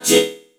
TCHI VOC.wav